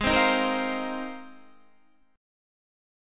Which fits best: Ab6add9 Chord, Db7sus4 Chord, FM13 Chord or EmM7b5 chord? Ab6add9 Chord